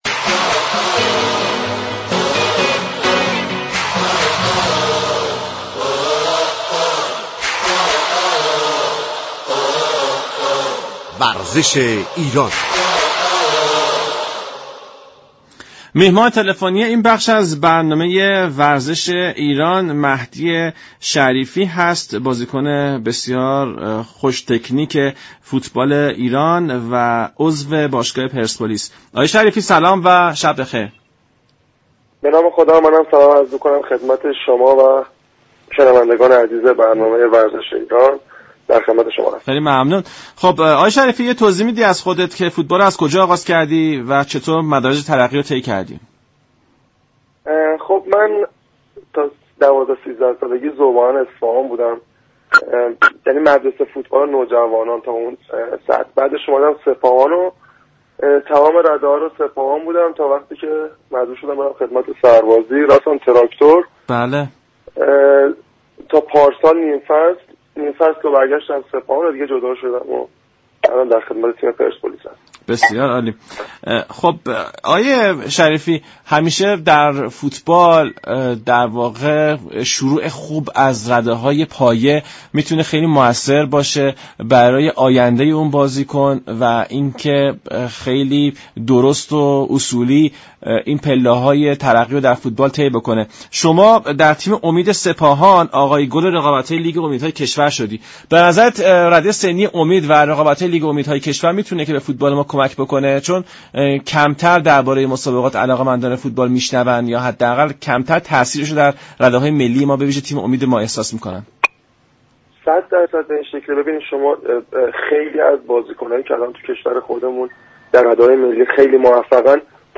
«مهدی شریفی» بازیكن خوش تكنیك فوتبال ایران و باشگاه پرسپولیس تهران در گفت و گو رادیو ایران گفت.